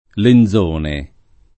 [ len z1 ne ]